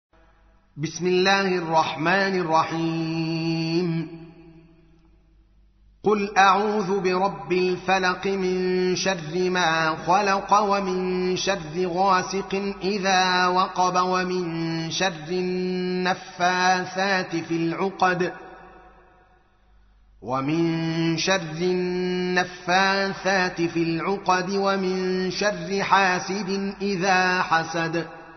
113. سورة الفلق / القارئ